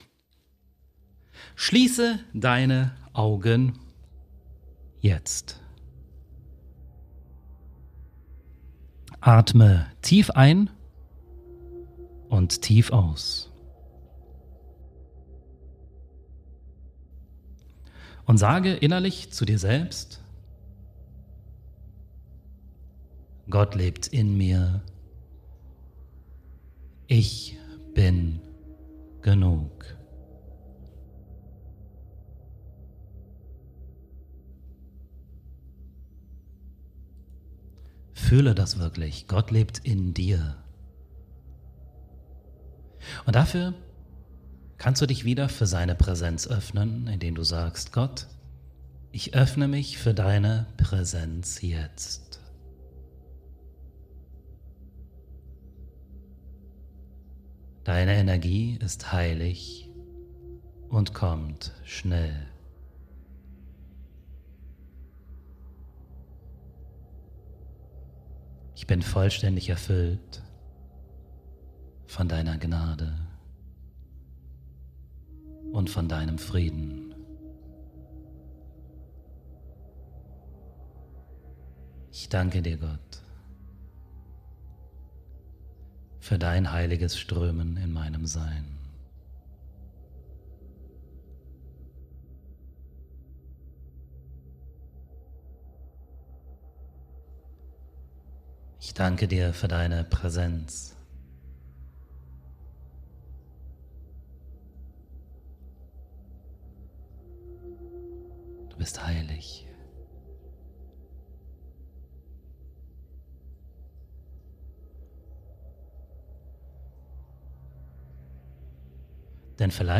Praktische Kurzmeditation als tägliche Kraftquelle